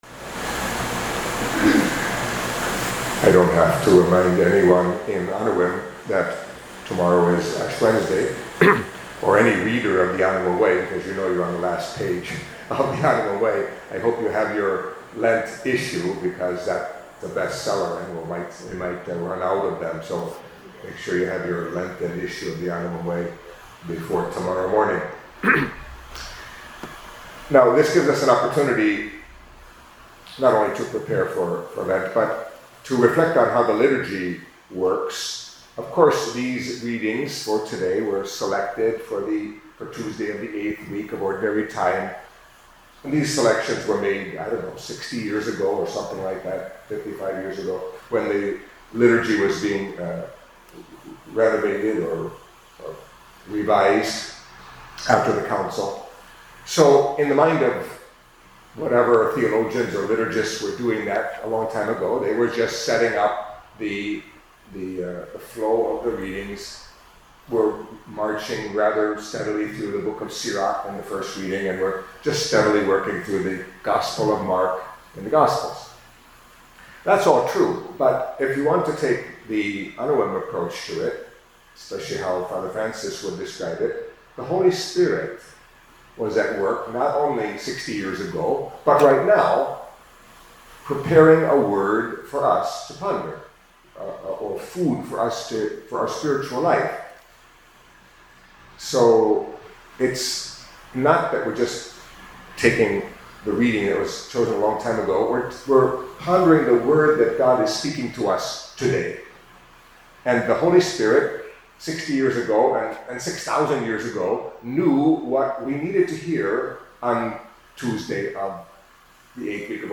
Catholic Mass homily for Tuesday of the Eighth Week in Ordinary Time